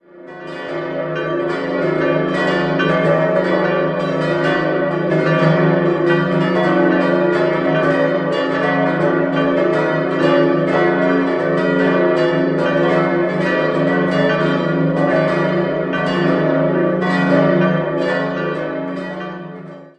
7-stimmiges Geläute: des'-es'-f'-as'-b'-des''-des''